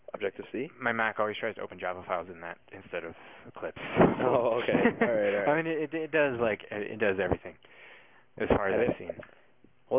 Functions of Bookended Narrow-Pitch-Range Regions
3. Grudging appreciation